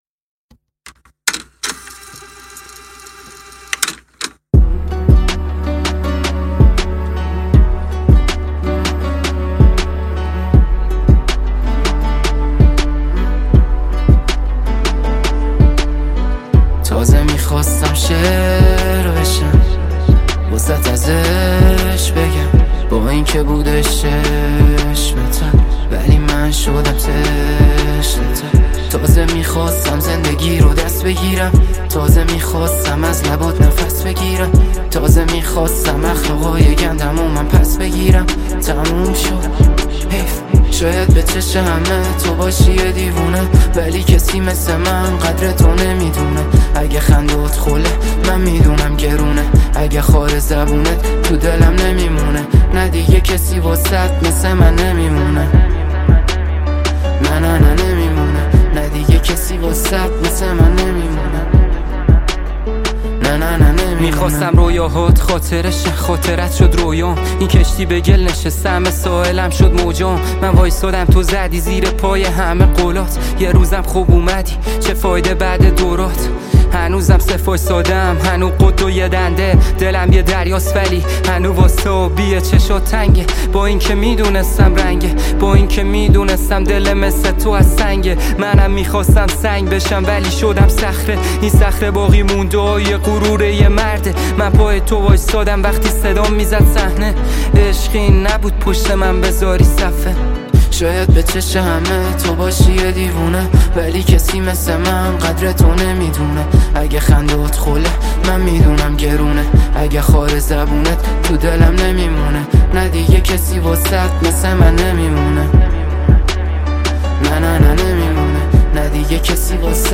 رپ
آهنگ با صدای زن
اهنگ ایرانی